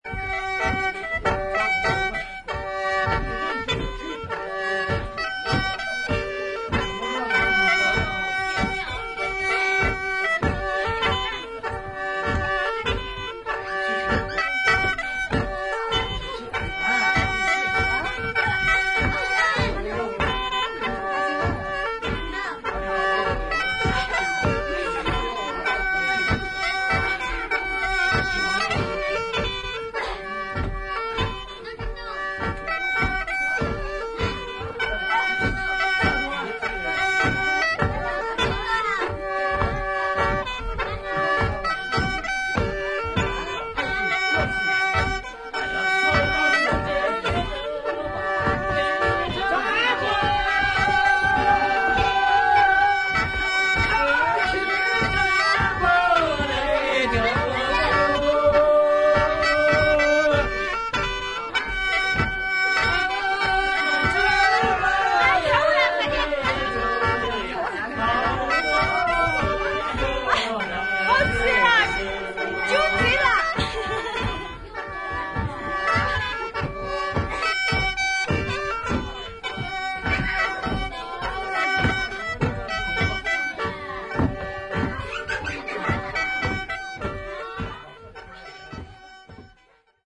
ひょうたん笛、笙、伝統的な弦楽器による音色や人々による唱法が独特の音の調和を生み出している素晴らしいフィールドレコーディング作品。